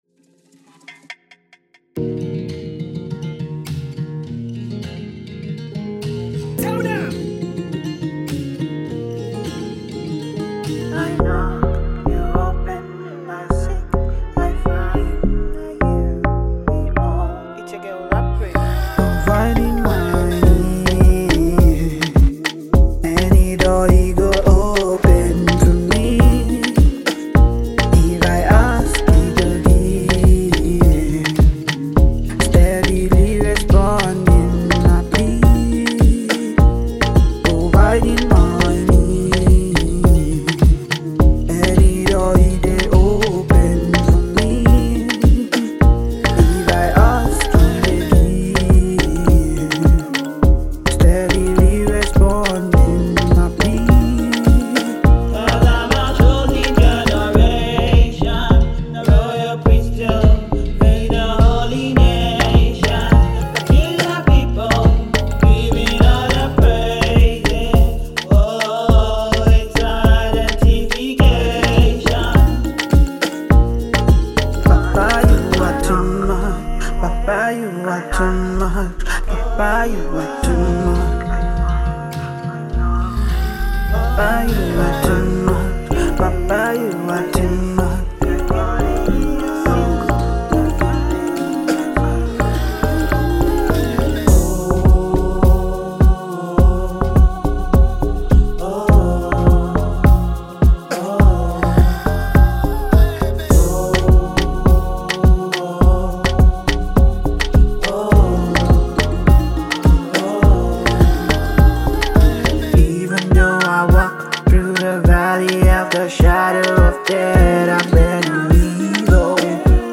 a bold and empowering anthem
the song pulses with energy, spirit, and authenticity.